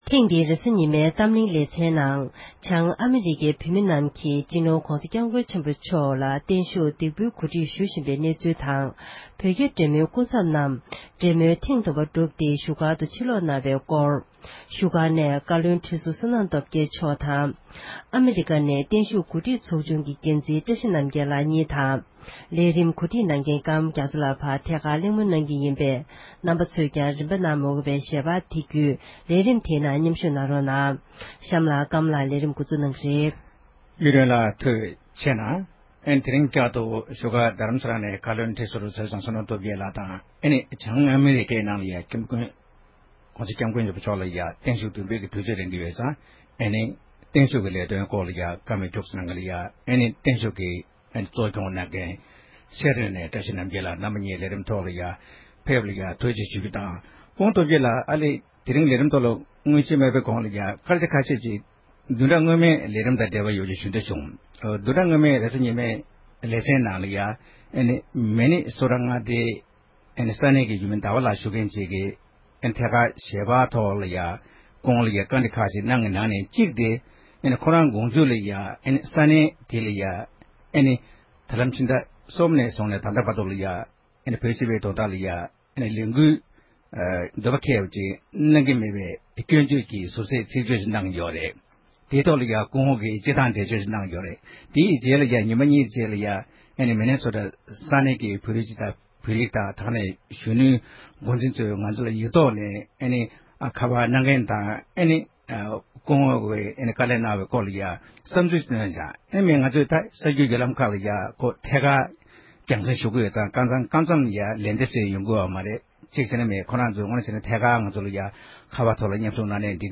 གཏམ་གླེང་གི་ལེ་ཚན